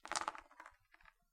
掷骰子的声音 木质表面
描述：染料被卷起的声音，我发现它无论是6面染料，4面染料，20面染料还是60面染料都没关系，它听起来都一样......是的我有60面染料......
标签： 板游戏 骰子 染料
声道立体声